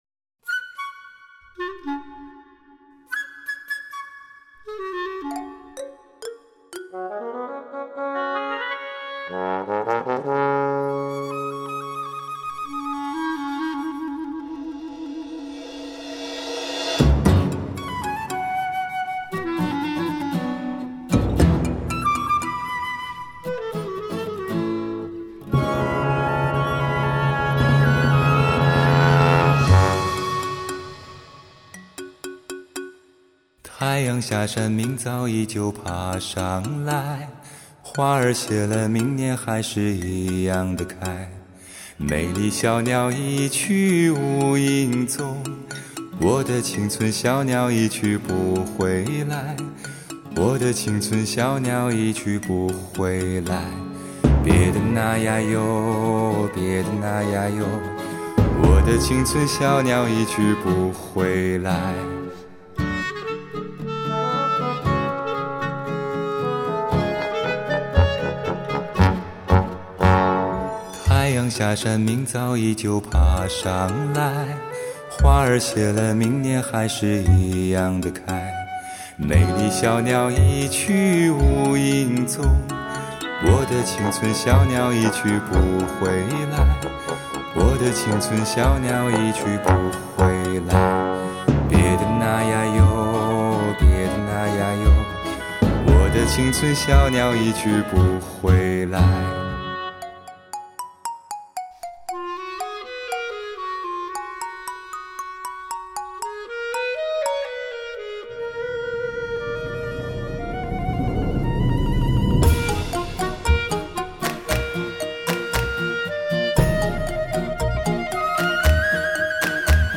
[分享]来听人声低音炮！----之四